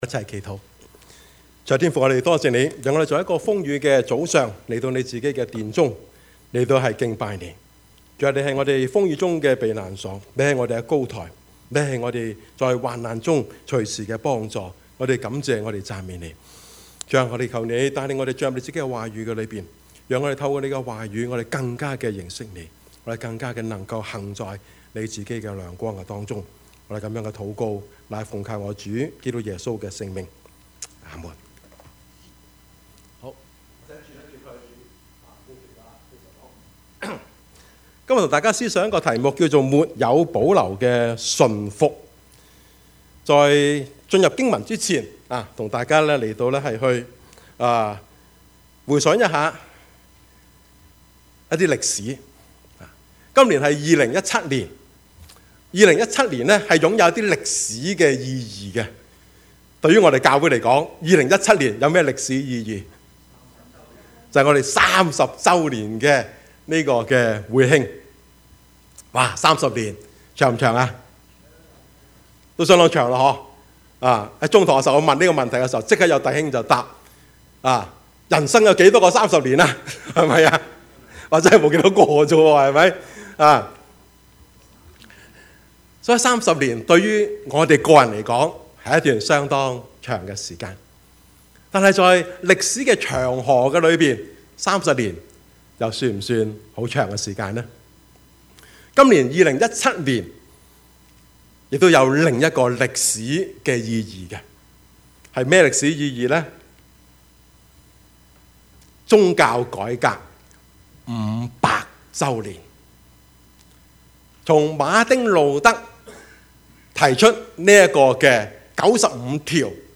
Service Type: 主日崇拜
Topics: 主日證道 « 來吧!